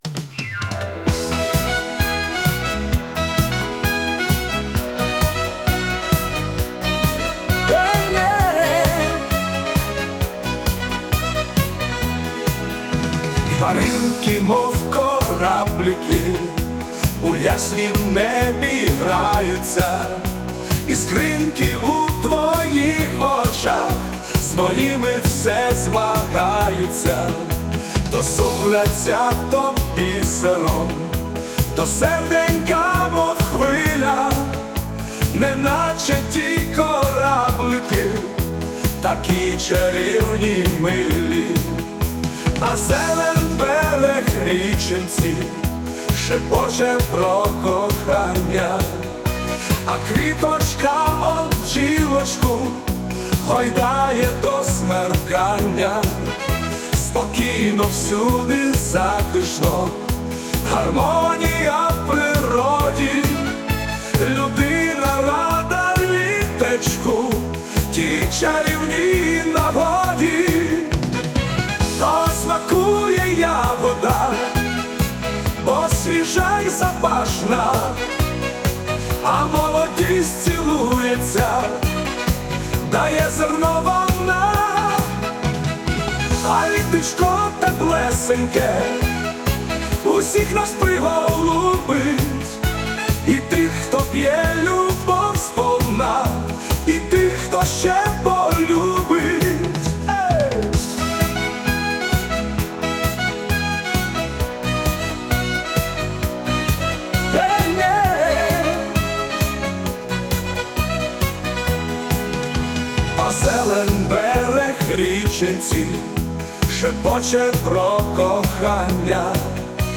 Музика і виконання ШІ
ТИП: Пісня
СТИЛЬОВІ ЖАНРИ: Ліричний